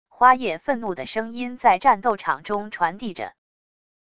花叶愤怒的声音在战斗场中传递着wav音频